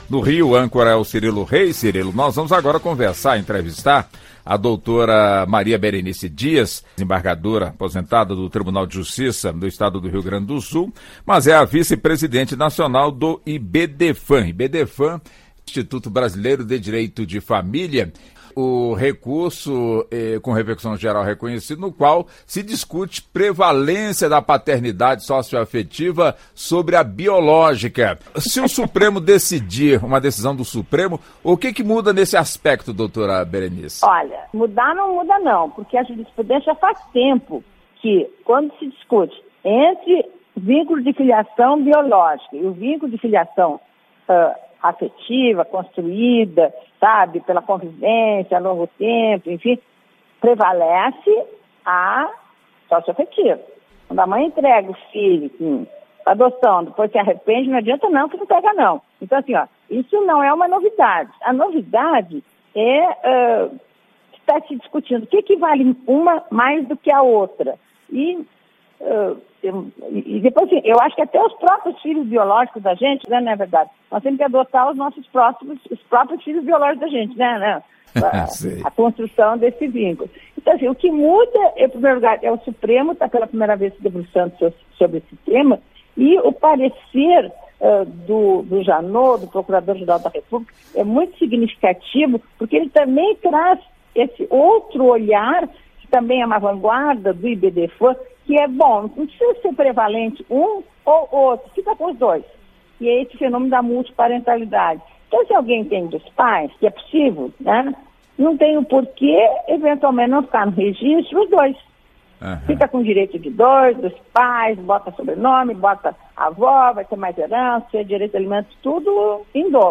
Entrevista: Saiba mais sobre a prevalência da paternidade sócio-afetiva sobre a biológica